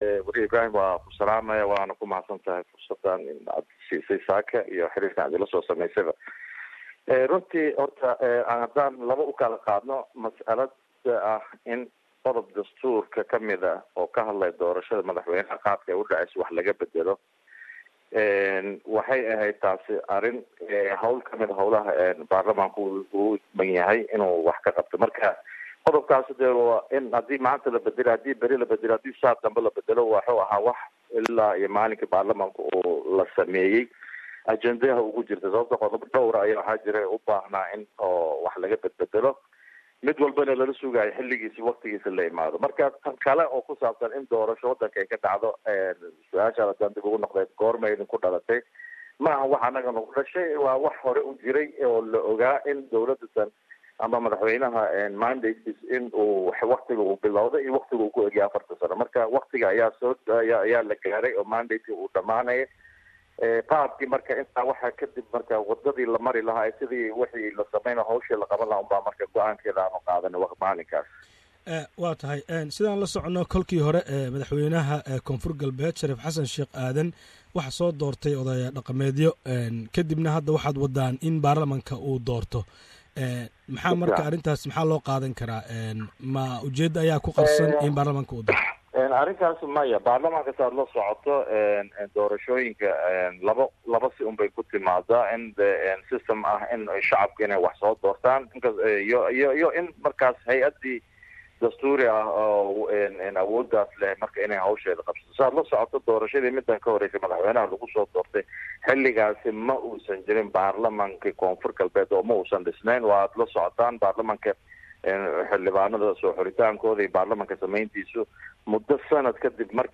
Wareysi uu siiyey Idaacada SBS Somali Gudoomiyaha Baarlamanka Koonfur Galbeed Cabdulqaadir Shariif Sheekhunaa ayaa waxa uu sheegay in 17ka Bihs November Sanadkan ay dhicidoonto Doorashada Madaxweyne ee Maamulka Koonfur Galbeed